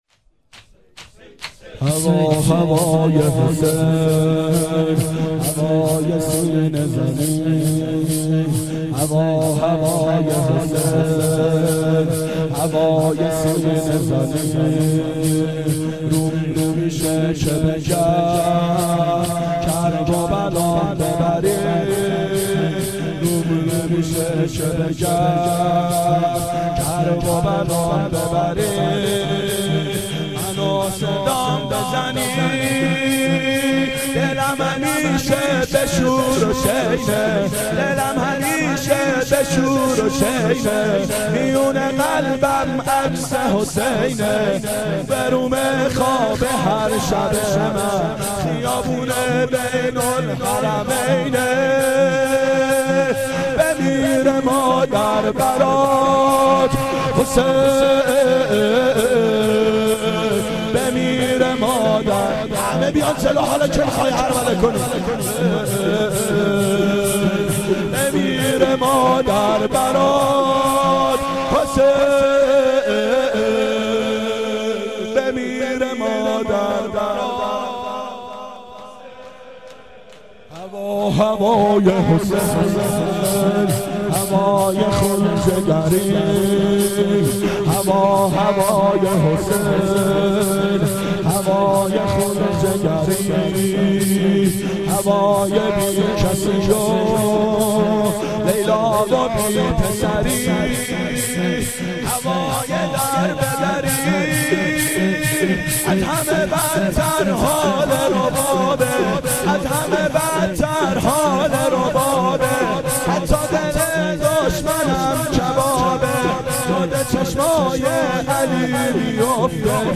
هوای سینه زنی
گلچین سال 1389 هیئت شیفتگان حضرت رقیه سلام الله علیها